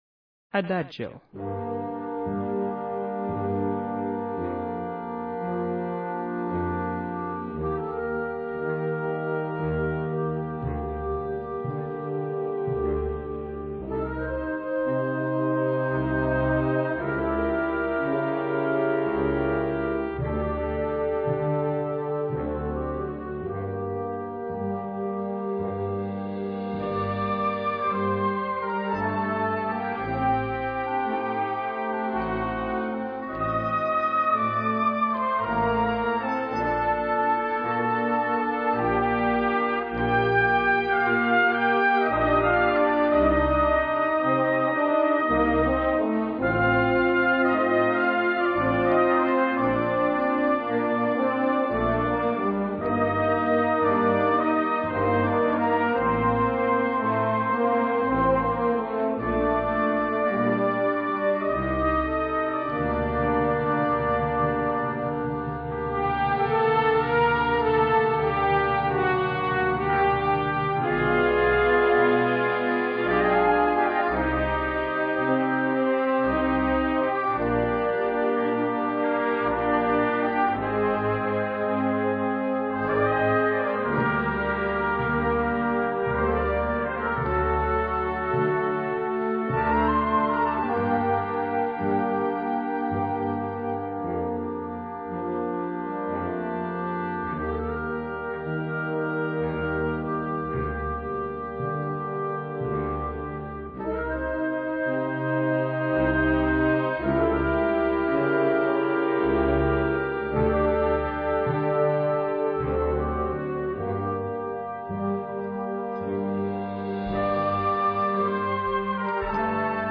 Besetzung: Blasorchester
Die Verwendung des Schlagzeuges ist optional.